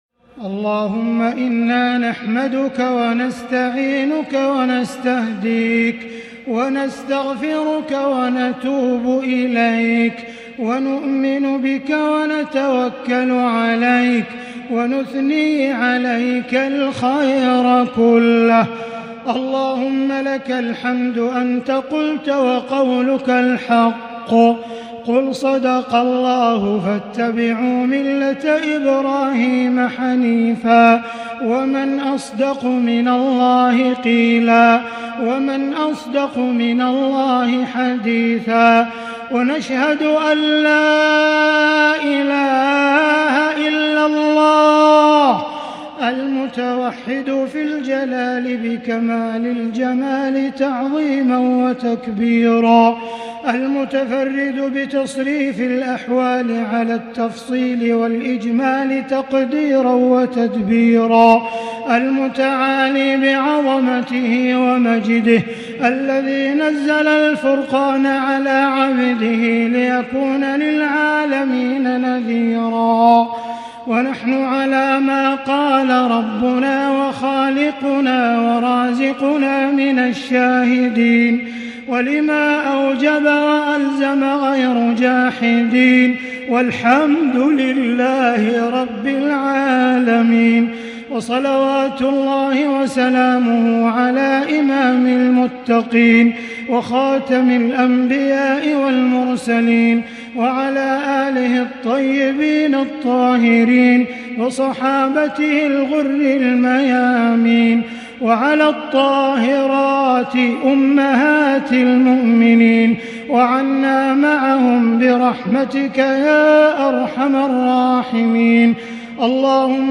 دعاء ختم القرآن ليلة 29 رمضان 1442هـ | Dua for the night of 29 Ramadan 1442H > تراويح الحرم المكي عام 1442 🕋 > التراويح - تلاوات الحرمين